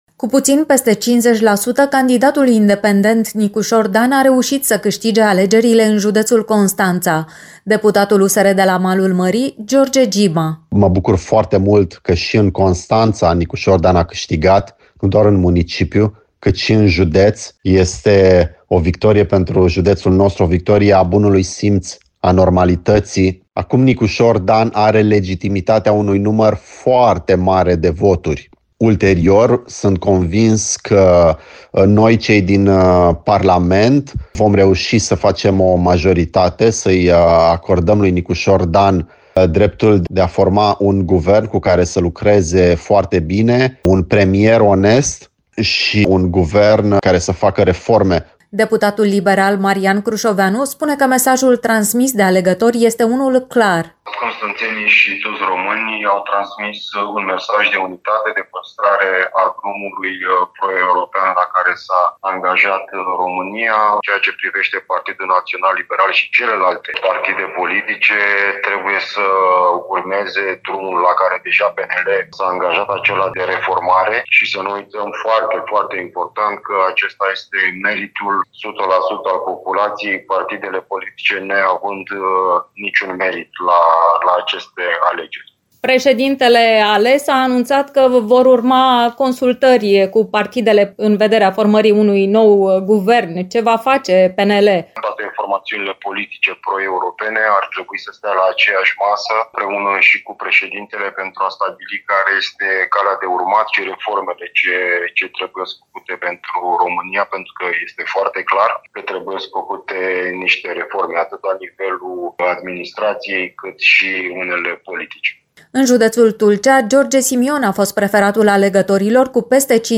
Deputatul liberal Marian Crușoveanu spune că mesajul transmis de alegători este unul clar.
Deputatul AUR, Elena Doboș, spune că formațiunea politică va urma aceeași linie în Parlament.
Ales deputat pe listele PSD Tulcea, Sergiu Constantinescu spune că politicienii trebuie să lupte acum să unească cele două Românii în care s-a scindat țara în ultima perioadă.